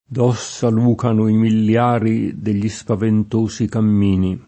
miliario [milL#rLo] s. m. (archeol.); pl. ‑ri (raro, alla lat., ‑rii) — raro milliario [millL#rLo]: D’ossa lùcono i milliari Degli spaventosi cammini [
d 0SSa l2kono i millL#ri del’l’i Spavent1Si kamm&ni] (D’Annunzio) — cfr. miliare; milione